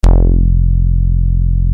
Funk Elastique Bass JD 990 F2 elasti_bass
elasti_bass.mp3